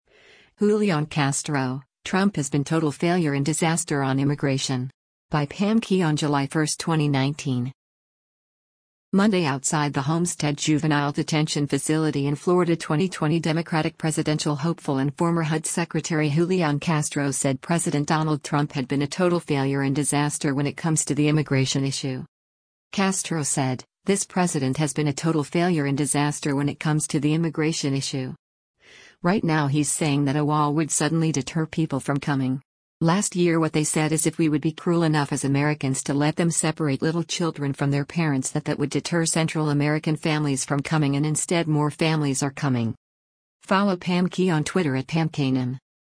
Monday outside the Homestead Juvenile Detention Facility in Florida 2020 Democratic presidential hopeful and former HUD Secretary Julián Castro said President Donald Trump had been a”total failure and disaster when it comes to the immigration issue.”